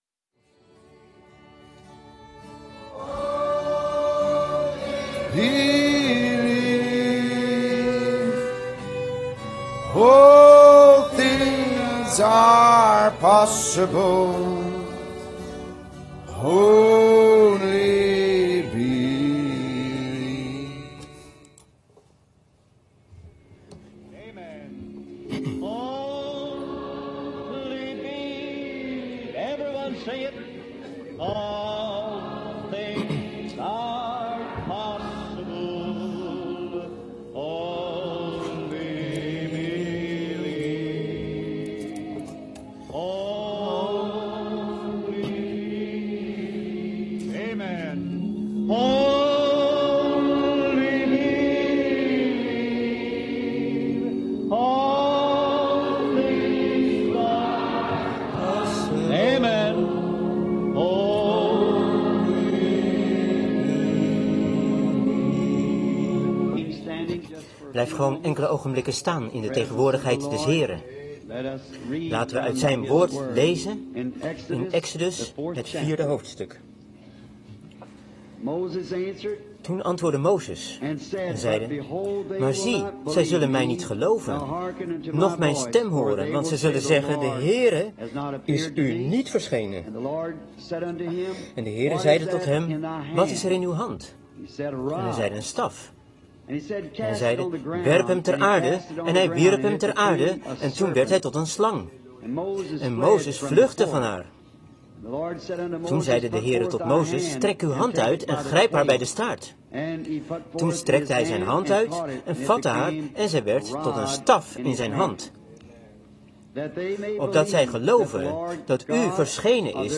Prediking
Locatie Denham Springs high school Denham Springs , LA